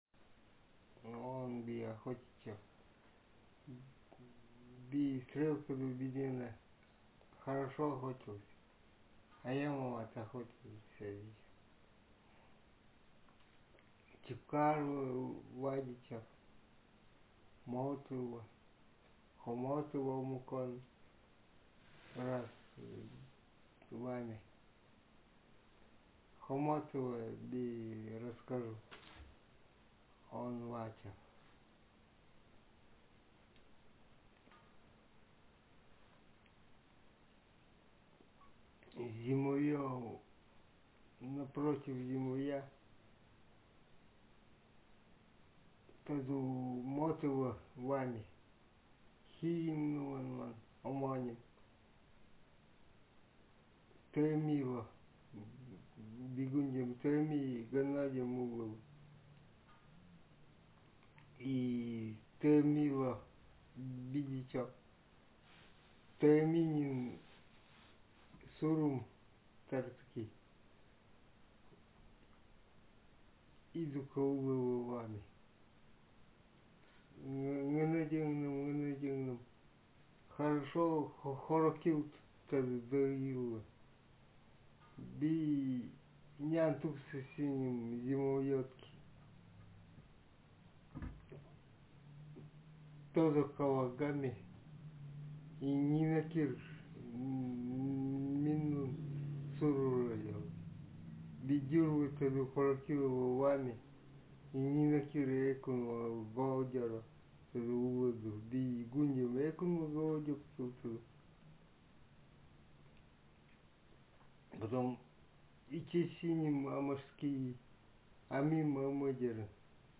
Interlinear glossed text
Speaker sexm
Text genrepersonal narrative